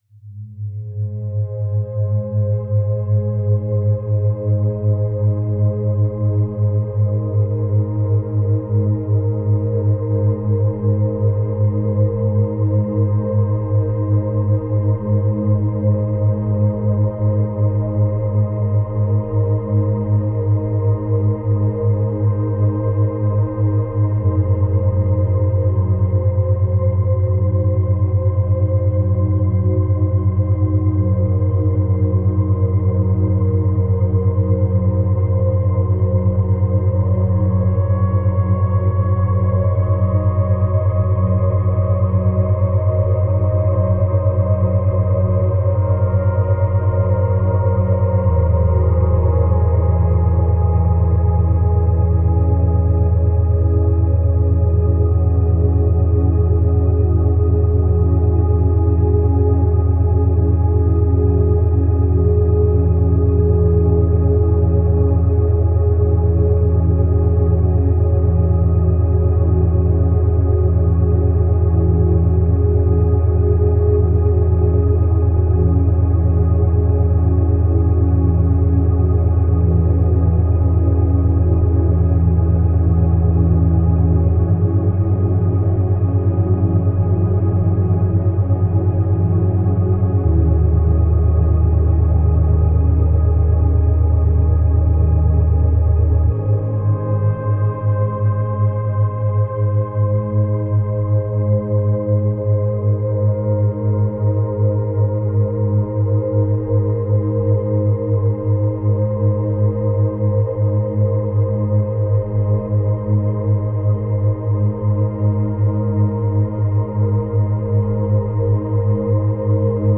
101Hz – 109Hz
در این فصل قرار هست با ضرباهنگ‌های دوگانه آشنا بشیم. گروهی از این فرکانس‌ها به خواب و آرامش شما کمک می‌کنن و گروه دیگشون برای افزایش تمرکز هستن، این امواج میتونن در حین مدیتیشن، حین انجام کار و یا قبل خواب گوش داده بشن.